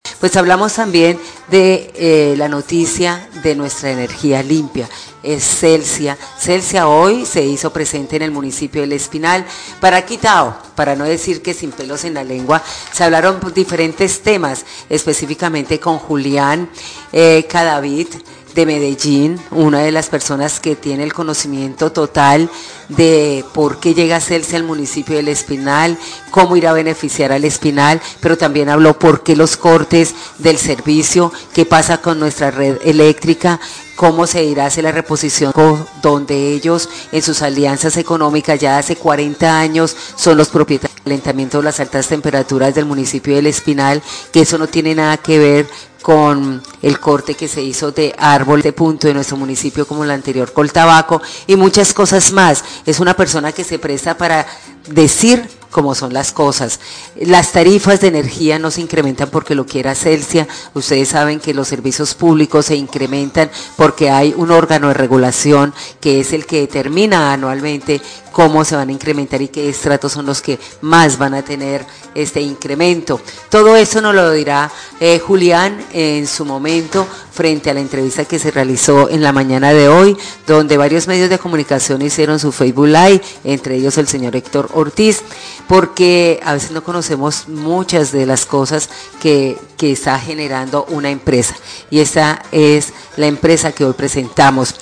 Radio
rueda de prensa